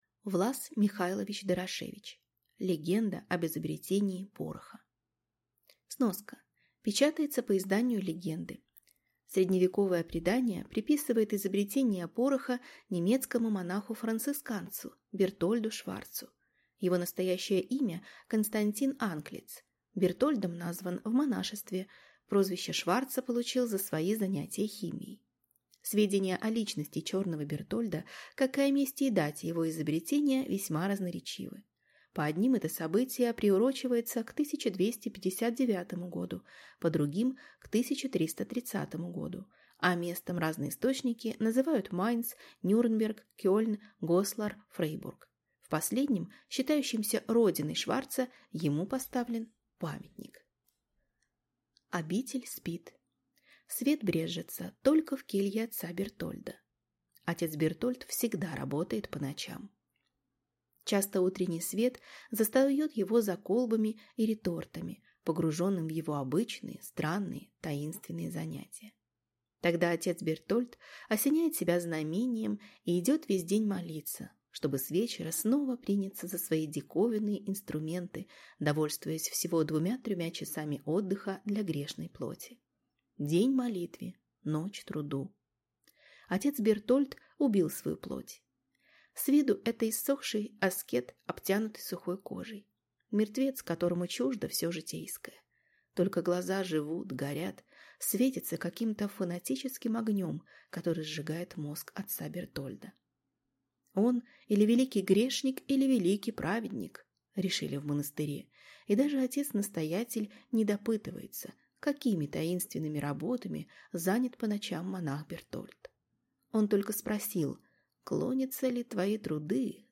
Аудиокнига Легенда об изобретении пороха | Библиотека аудиокниг